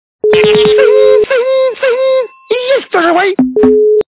При прослушивании Дзынь, дзынь, дзынь. - Есть кто живой? качество понижено и присутствуют гудки.